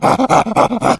Dragons roar and goblins squeak.